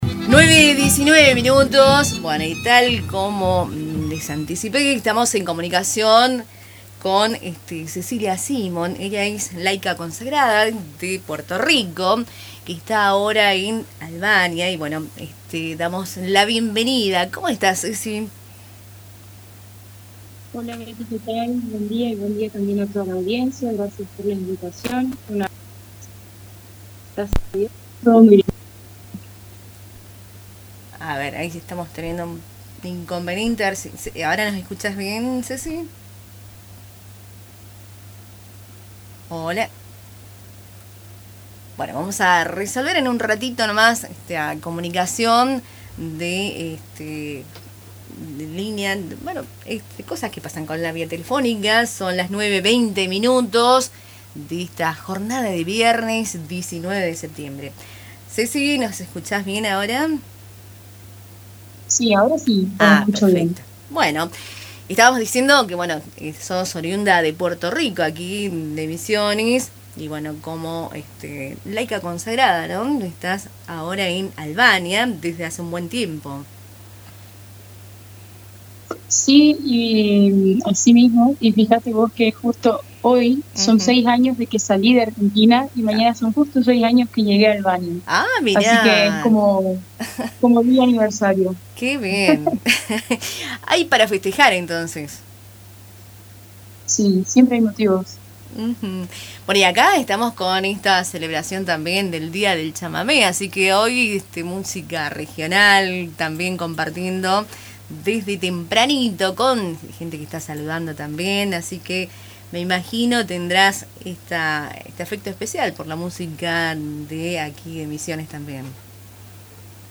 En diálogo con Radio Tupambaé